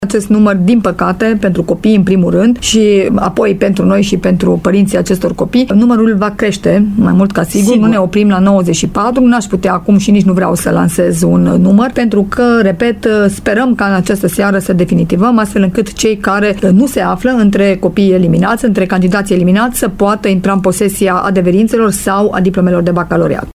Şeful Inspectoratului Şcolar Timiş, Aura Danielescu, a confirmat pentru Radio Timişoara, că numărul elevilor excluşi din examen va creşte: